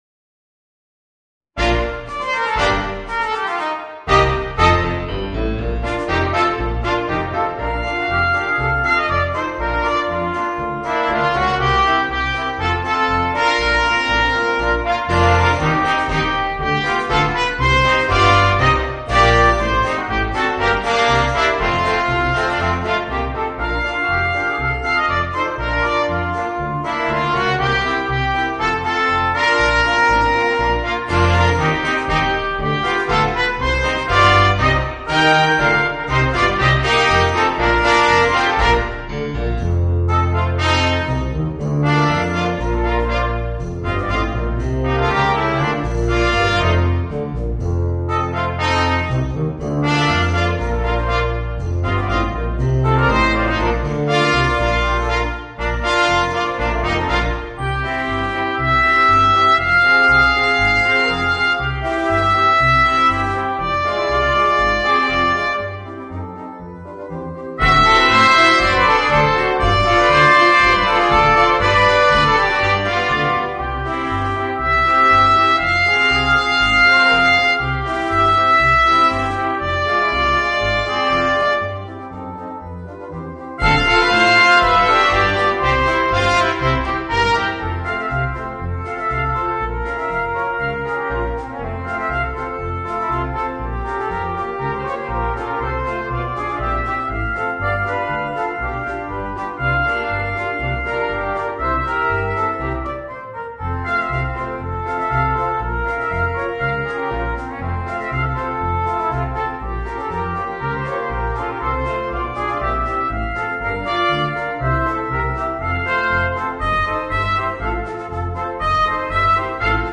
Voicing: 4 - Part Ensemble and Piano